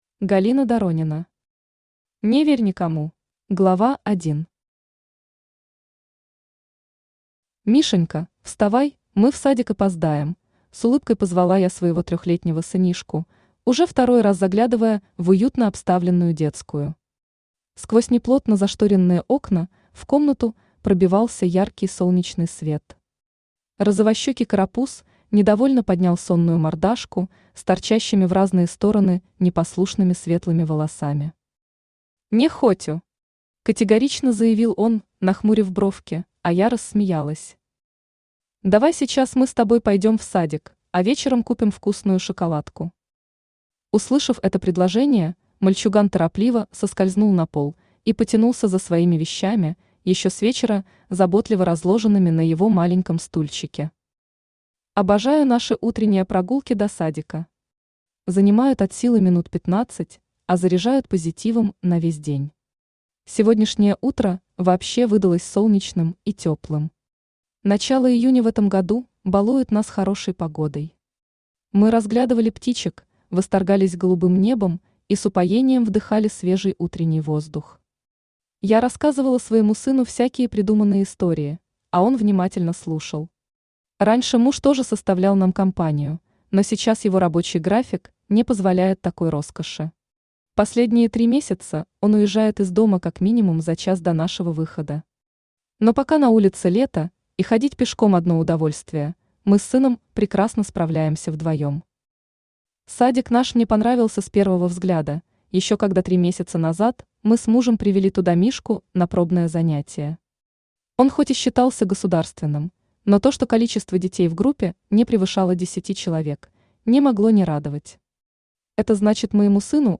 Aудиокнига Не верь никому Автор Галина Доронина Читает аудиокнигу Авточтец ЛитРес.